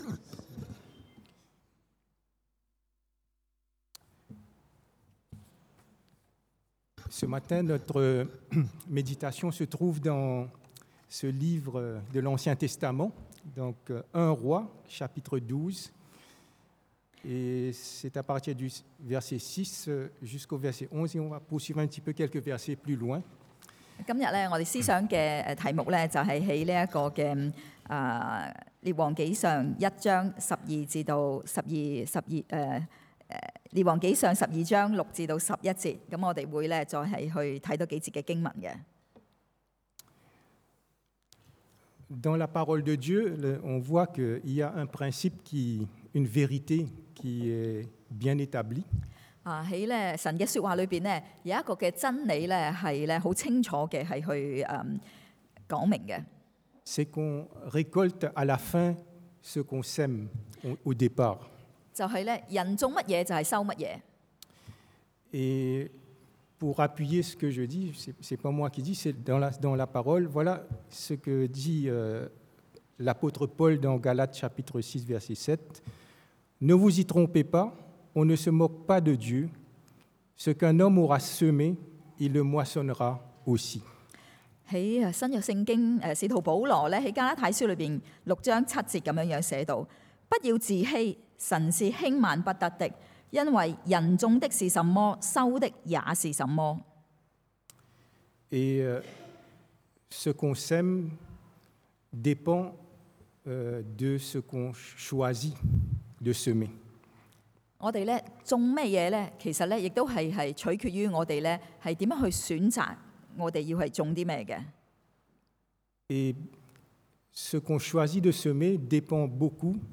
Les bons et les mauvais choix 好與壞的選擇 – Culte du dimanche